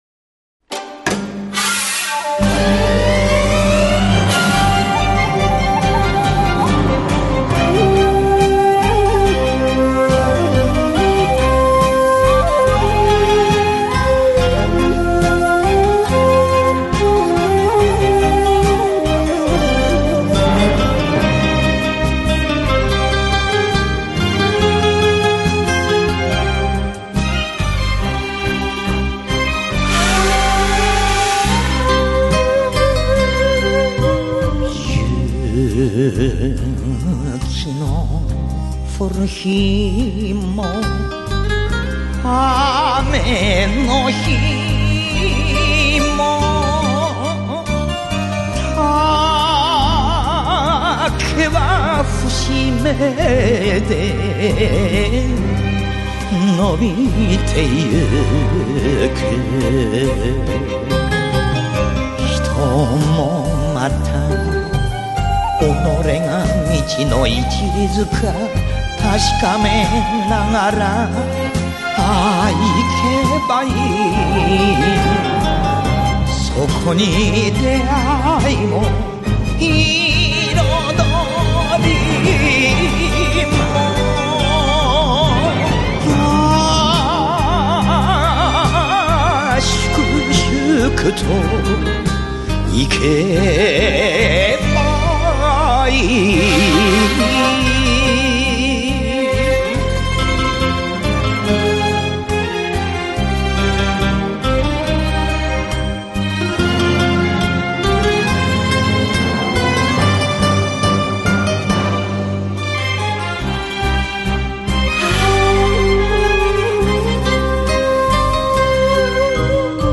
代流行曲的唱法熔为一炉，形成了自己独特的演唱技法。